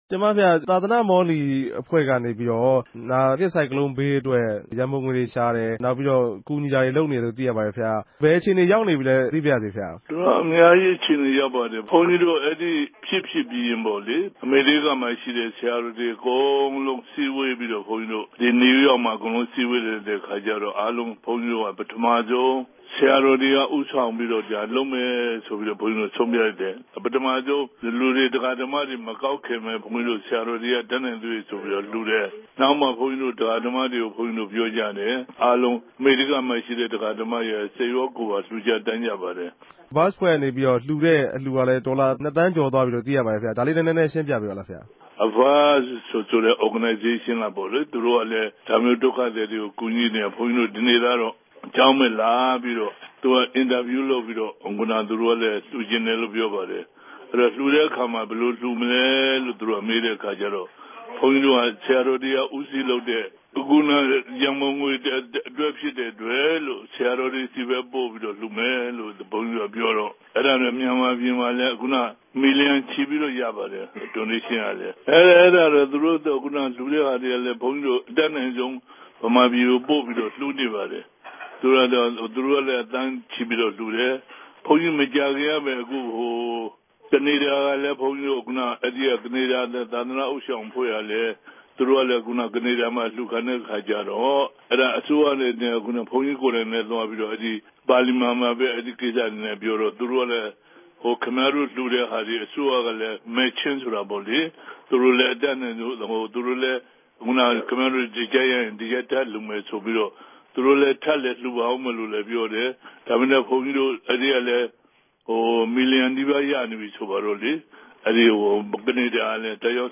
လ္တေွာက်ထားမေးူမန်းခဵက်။